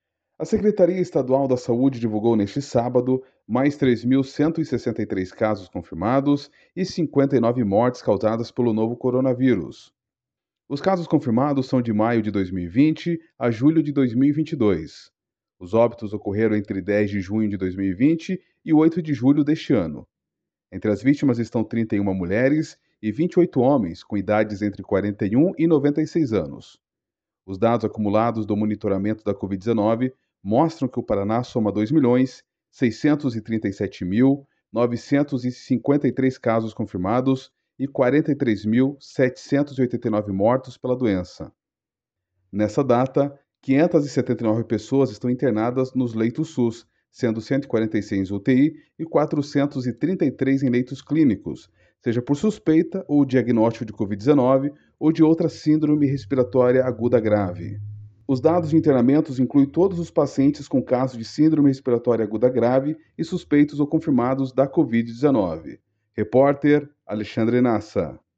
BOLETIM COVID 9 DE JULHO.mp3